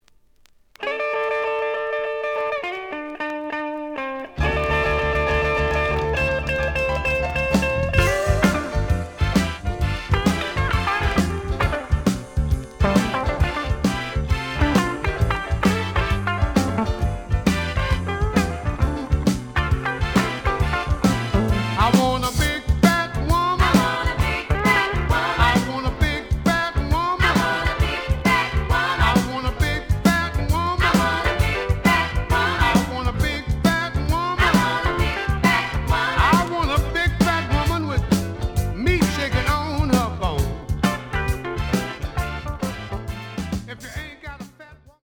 The audio sample is recorded from the actual item.
●Genre: Blues
Slight edge warp.